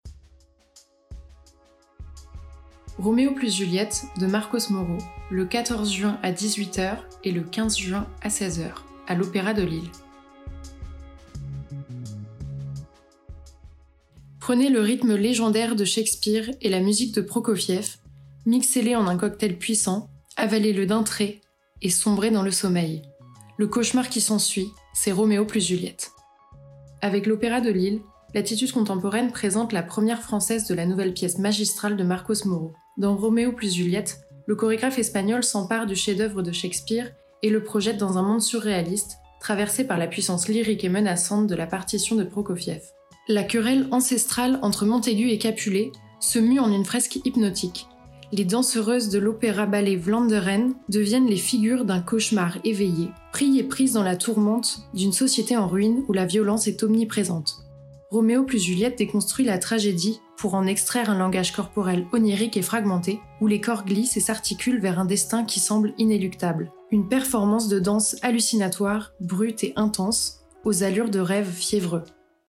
Version audio de la description du spectacle :